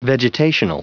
Prononciation du mot vegetational en anglais (fichier audio)
Prononciation du mot : vegetational